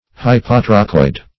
Search Result for " hypotrochoid" : The Collaborative International Dictionary of English v.0.48: Hypotrochoid \Hy`po*tro"choid\, n. [Pref. hypo- + trochoid.]